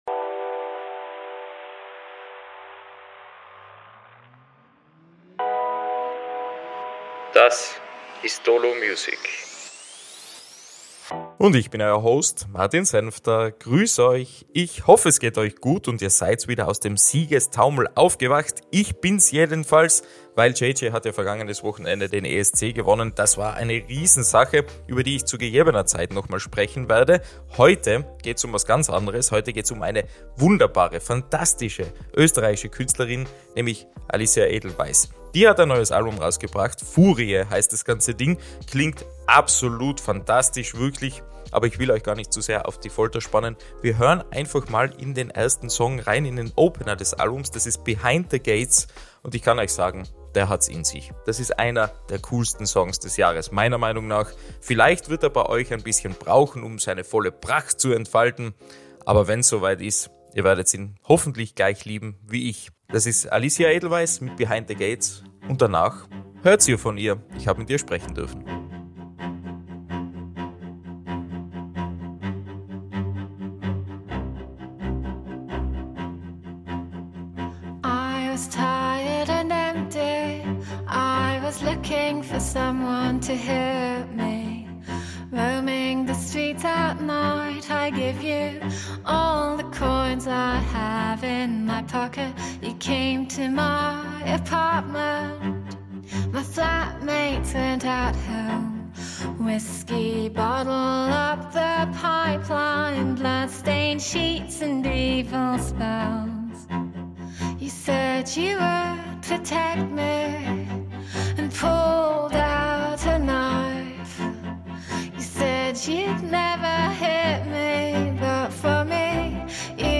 Akkordeon, Blockflöte, Streicher - alles hat irgendwie Platz und zeichnet das Soundbild der österreichischen Musikerin aus.